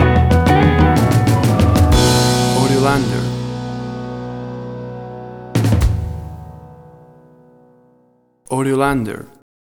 Tempo (BPM): 124